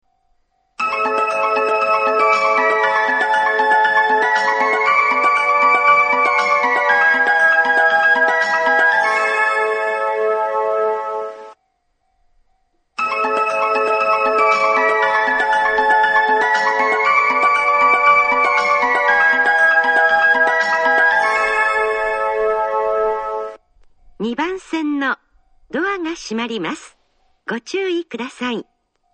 ２番線発車メロディー 曲は「木々の目覚め」です。